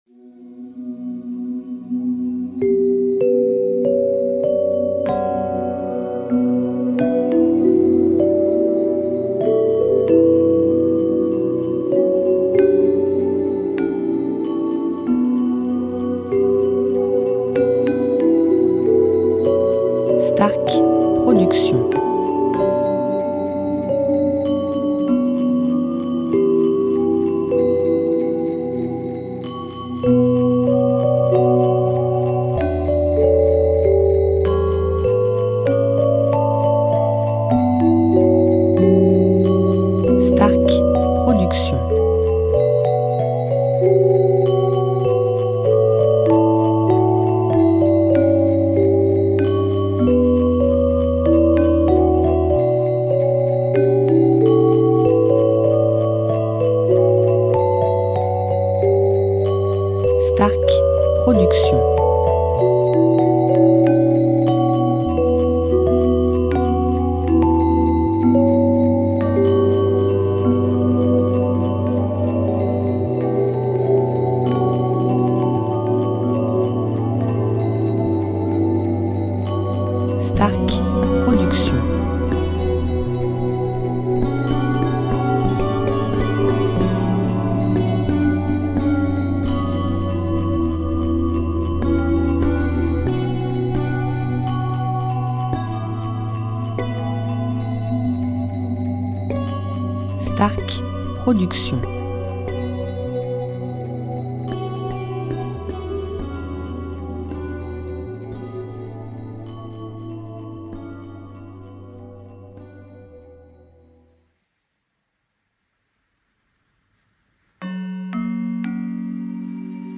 style Asie Balinais durée 1 heure